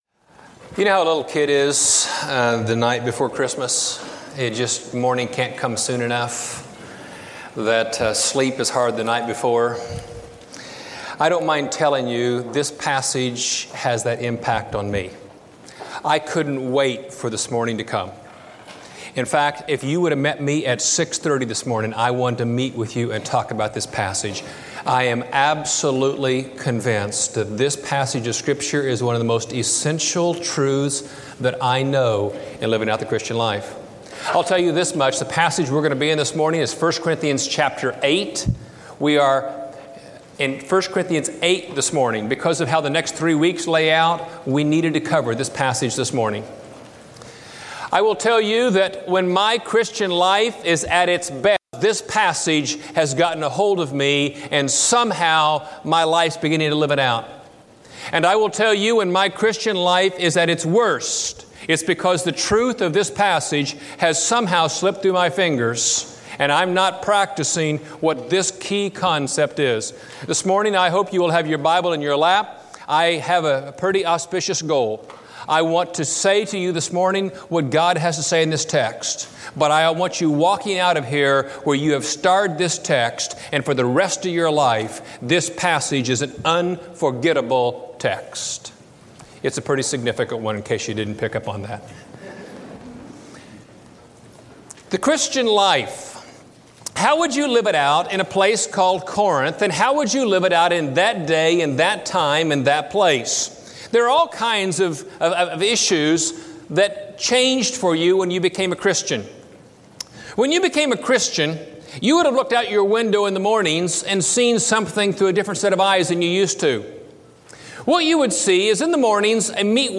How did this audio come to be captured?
Knowledge Puffs Up Preached at College Heights Christian Church October 2, 2005 Series: 1 Corinthians 2005 Scripture: 1 Corinthians 8 Audio